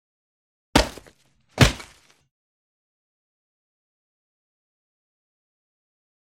Звук двойного удара топора о дерево